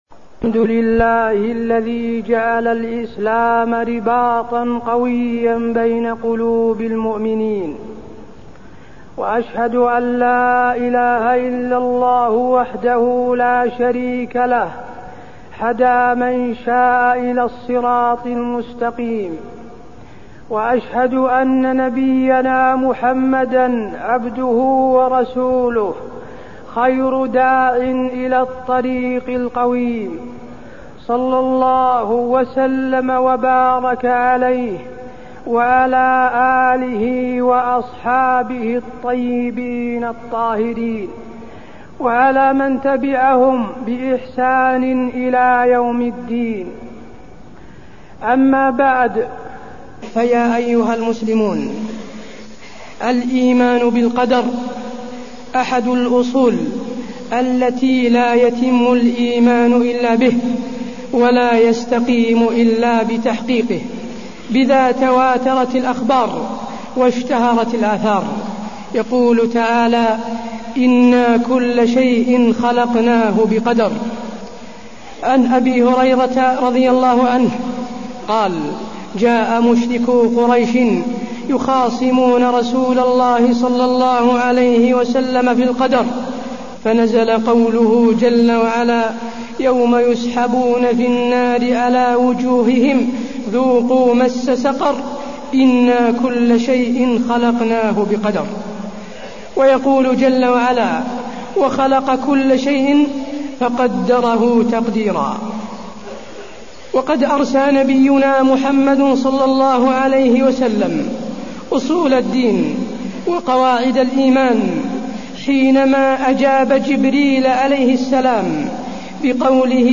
تاريخ النشر ٩ محرم ١٤٢١ هـ المكان: المسجد النبوي الشيخ: فضيلة الشيخ د. حسين بن عبدالعزيز آل الشيخ فضيلة الشيخ د. حسين بن عبدالعزيز آل الشيخ الإيمان بالقدر وفضل شهر محرم